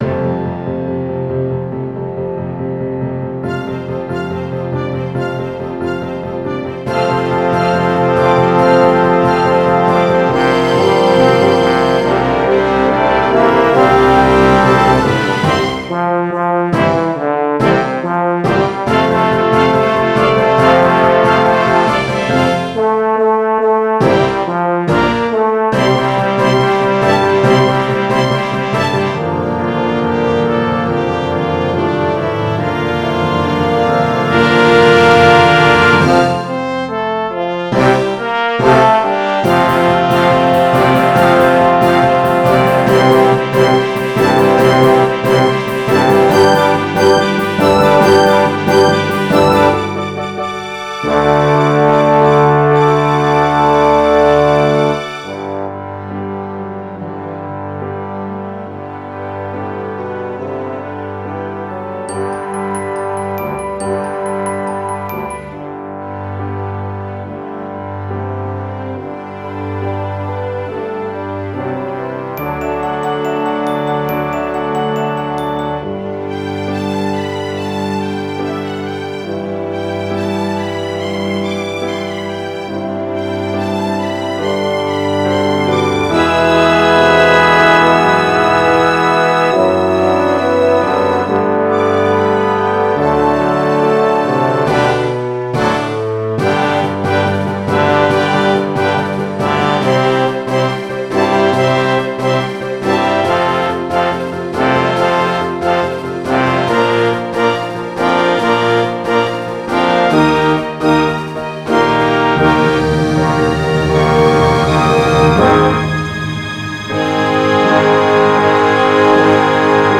Symphony Orchestra